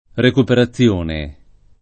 vai all'elenco alfabetico delle voci ingrandisci il carattere 100% rimpicciolisci il carattere stampa invia tramite posta elettronica codividi su Facebook recuperazione [ rekupera ZZL1 ne ] e recupero [ rek 2 pero ] → ricupero